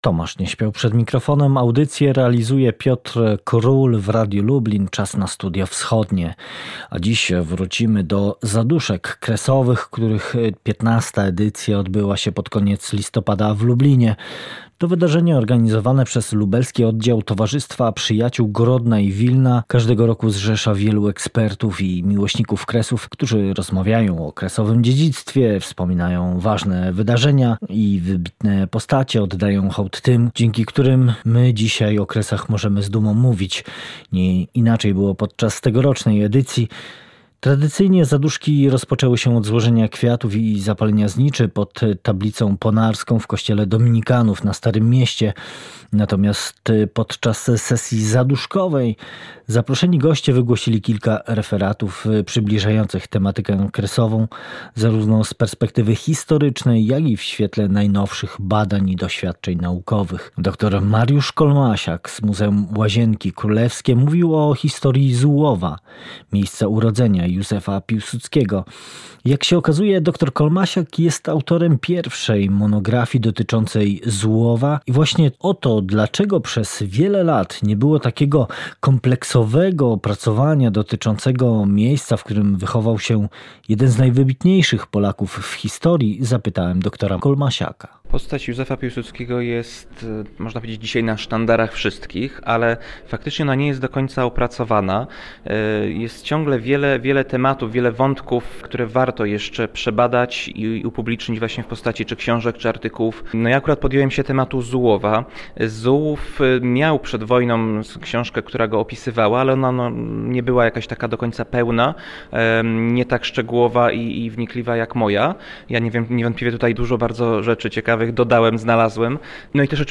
Byliśmy tam z mikrofonem.